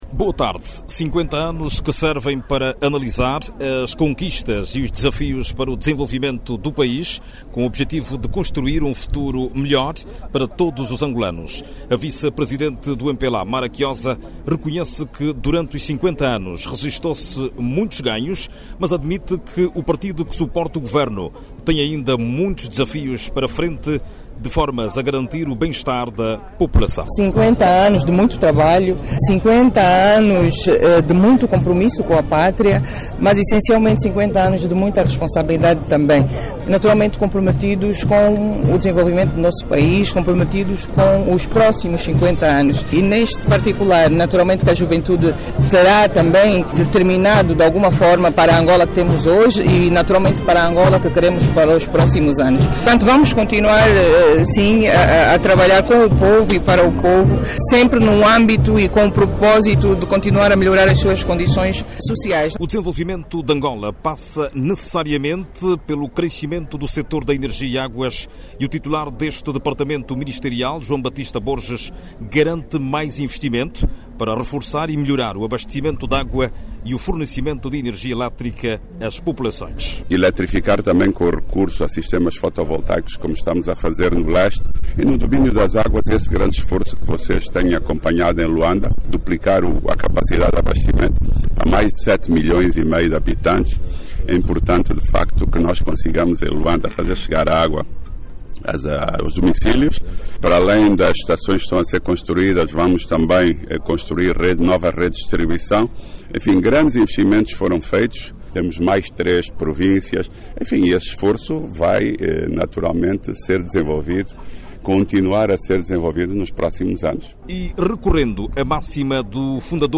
Tratou-se do primeiro momento oficial antes do acto central de comemoração dos 50 anos da Independência Nacional, que decorre neste momento aqui na Praça da República.